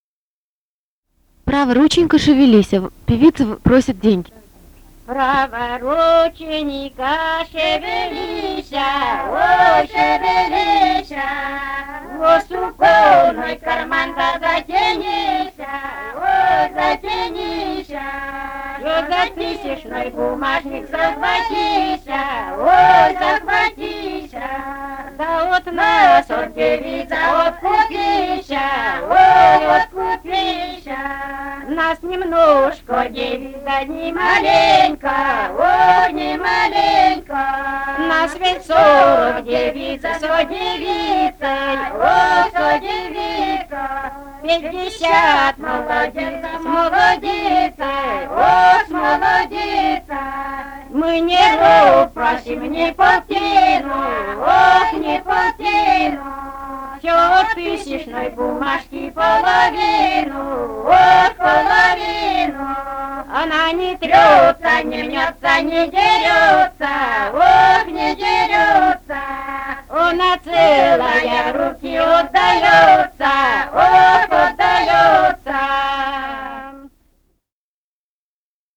Этномузыкологические исследования и полевые материалы
«Права рученька, шевелися» (свадебная).
Пермский край, д. Меньшиково Очёрского района, 1968 г. И1073-06